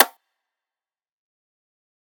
rim4.wav